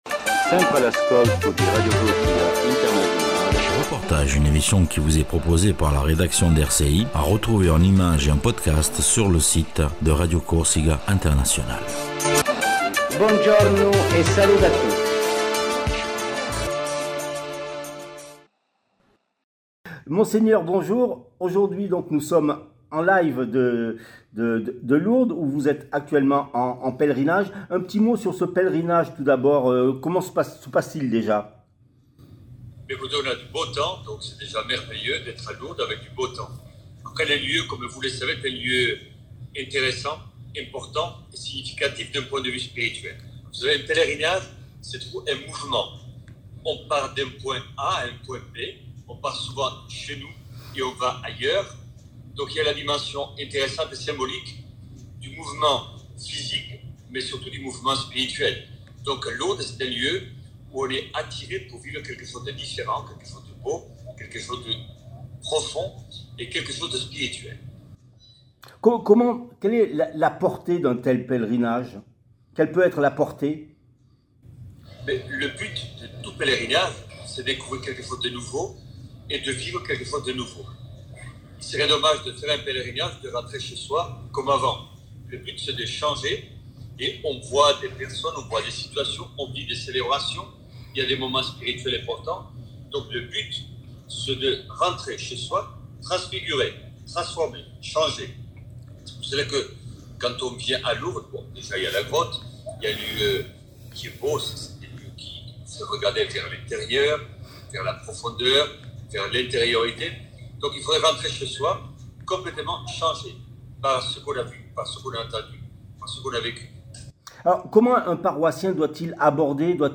RCI s'est entretenu avec l'évêque de Corse Mgr Bustillo.
REPORTAGE AVEC L EVEQUE DE CORSE MON Sgr Bustillo